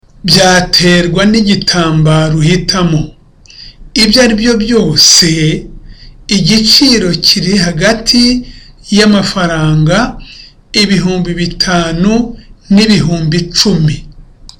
(With confidence.)